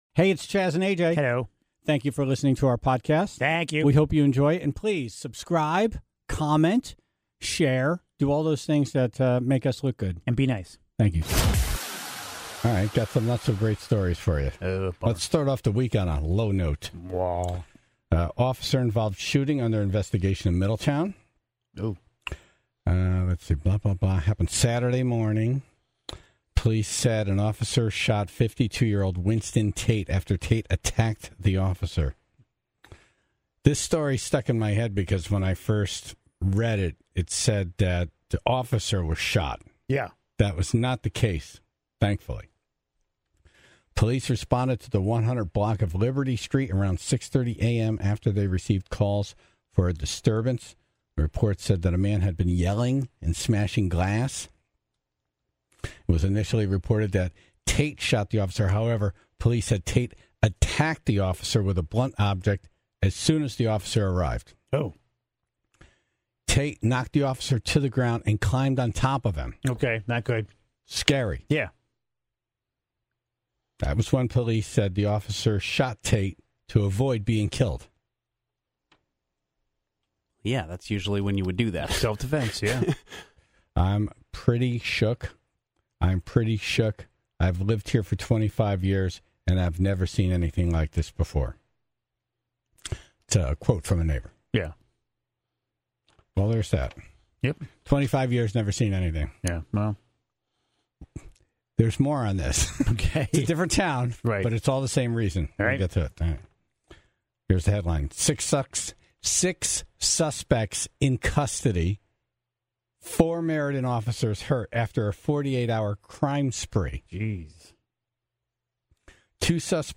She called in to explain what the cancellation announcement was like from the fans' perspective, and what this now means for a scheduled Connecticut show. (14:31) Dumb Ass News - Lady vs. robot in a grocery store.